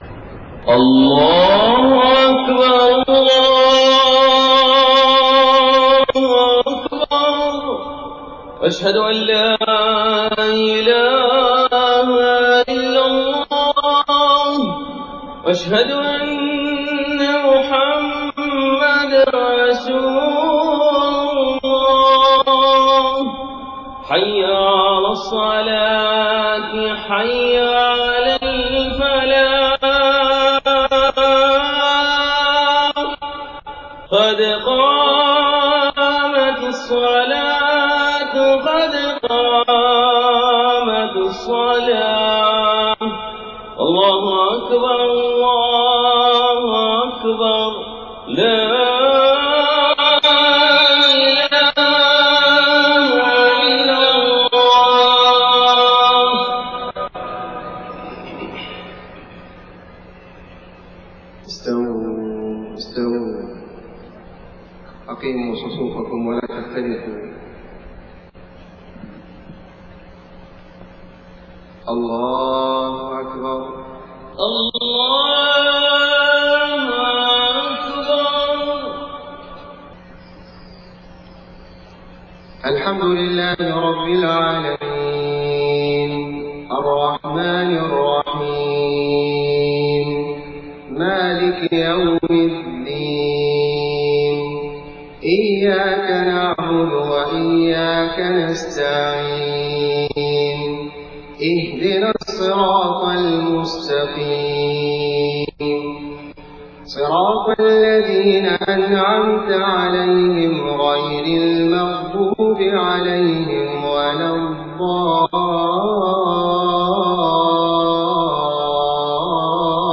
صلاة العشاء 8 صفر 1431هـ من سورة الإسراء 9-21 > 1431 🕋 > الفروض - تلاوات الحرمين